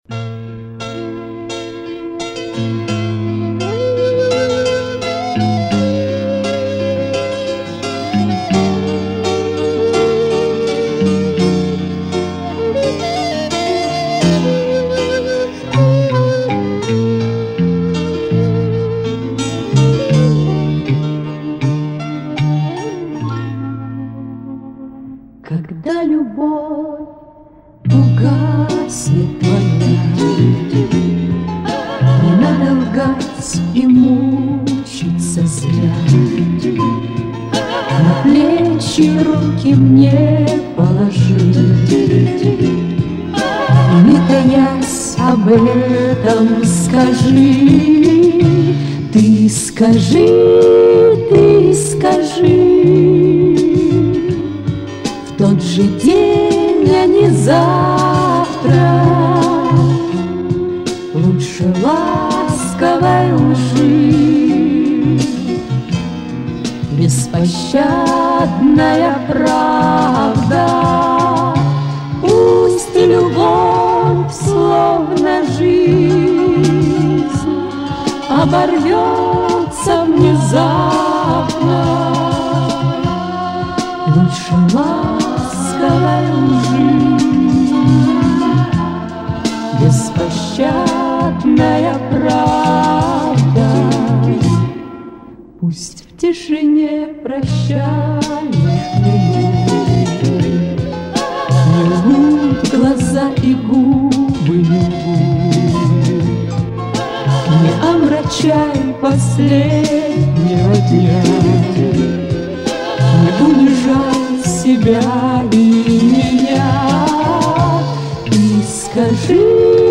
Душевно поют!
Все же минорный вариант Преснякова мне ближе.